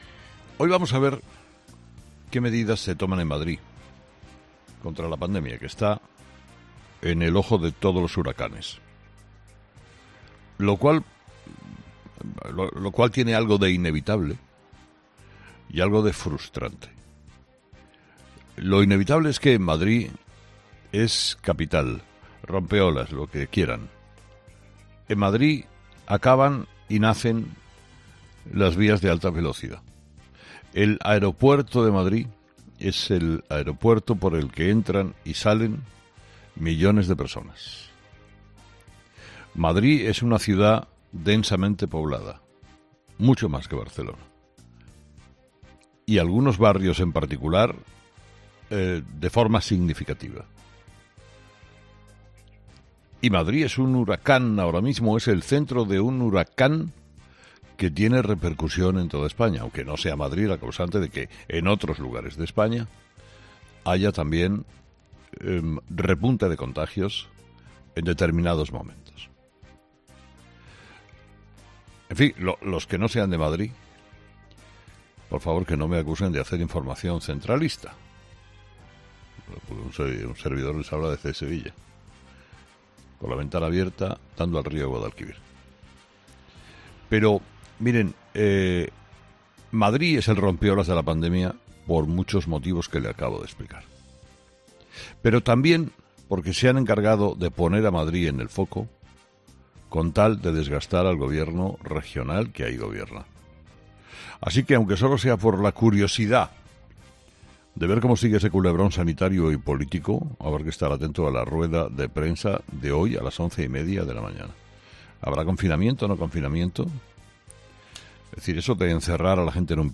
El director de 'Herrera en COPE', Carlos Herrera, ha analizado las claves de la posible reunión entre Ayuso y Sánchez para tratar el efecto de la pandemia en Madrid